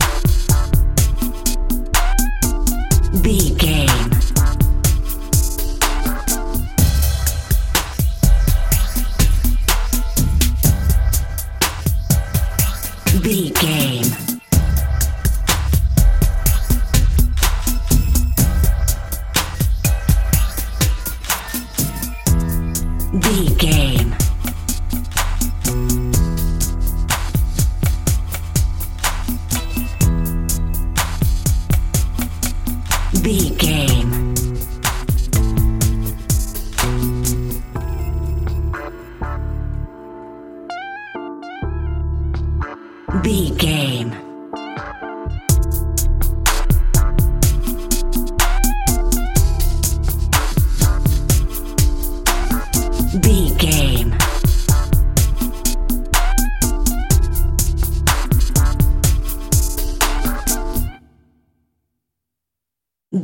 Aeolian/Minor
synthesiser
drum machine
hip hop
soul
Funk
acid jazz
r&b
energetic
bouncy
funky